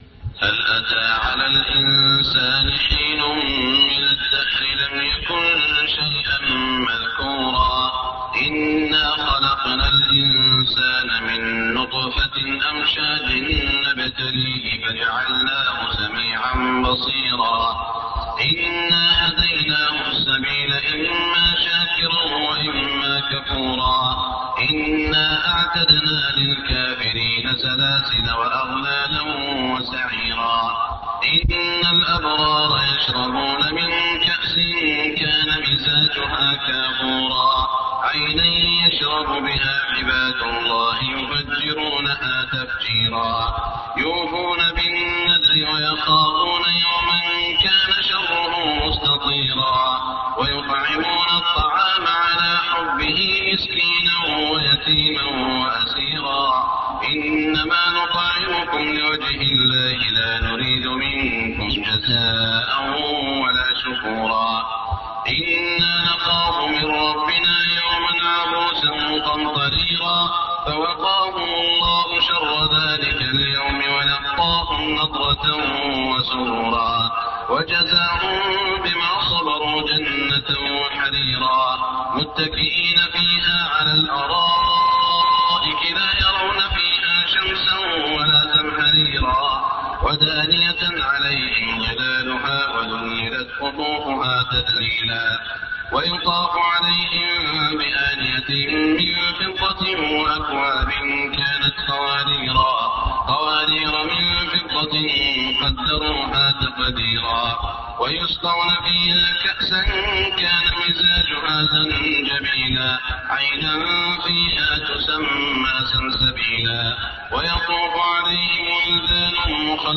صلاة الفجر 1422هـ من سورة الإنسان > 1422 🕋 > الفروض - تلاوات الحرمين